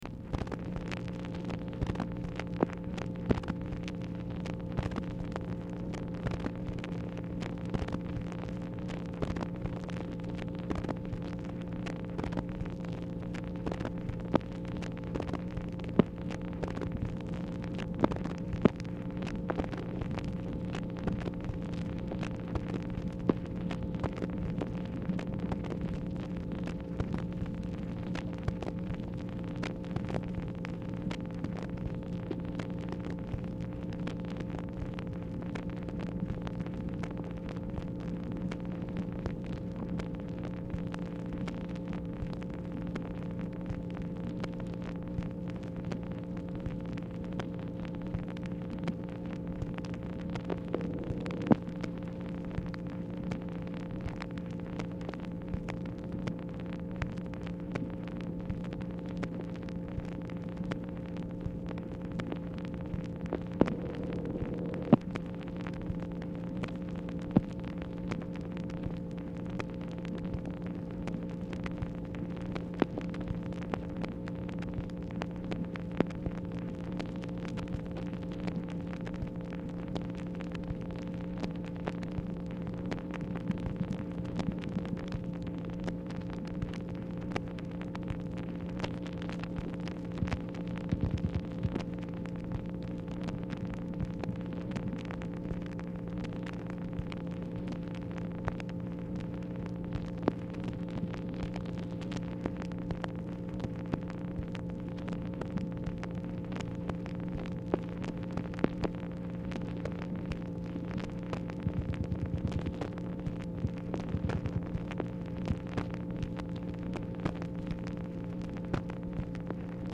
Telephone conversation # 4859, sound recording, MACHINE NOISE, 8/10/1964, time unknown | Discover LBJ